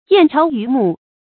燕巢于幕 yàn cháo yú mù 成语解释 燕子把窝做在帐幕上。